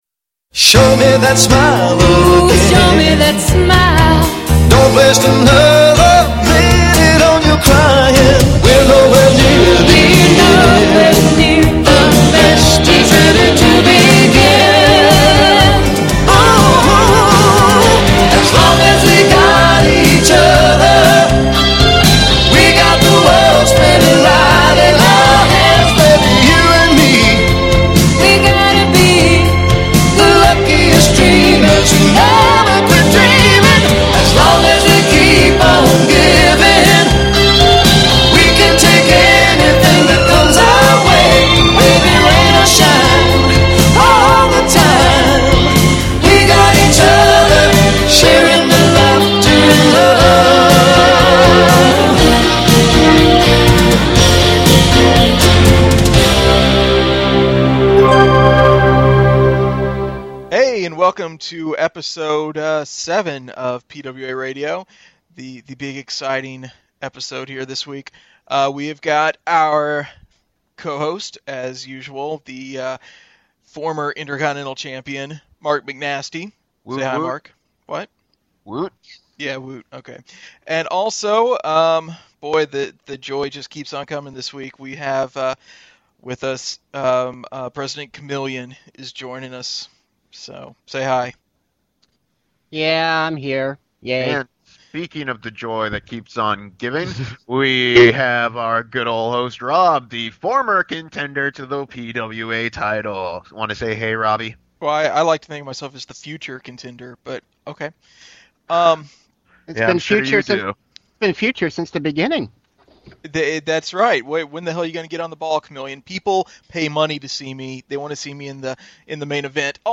Yet more technical difficulties plague us but I think I’ve more or less gotten a handle on them.
Anyway, the SNS interview is missing the second segment and the five questions because something happened during compression that made us sound like the Chipmunks.